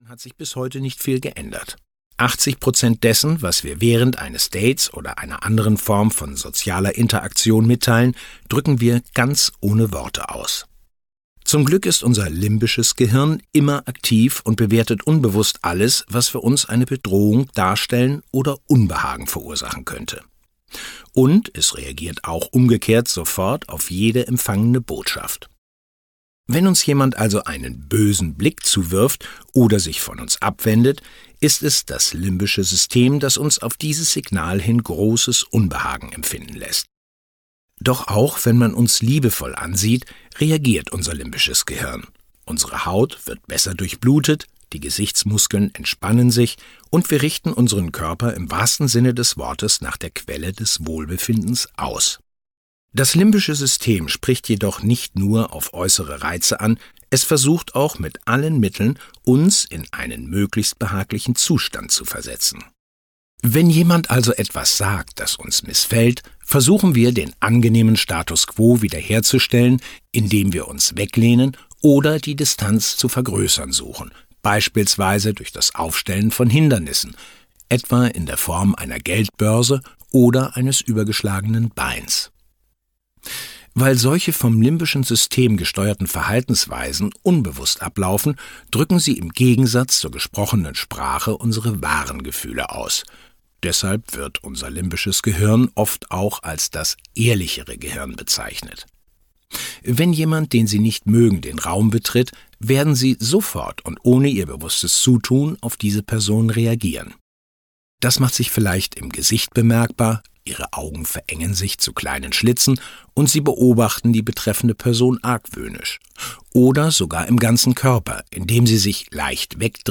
Die Körpersprache des Datings - Joe Navarro - Hörbuch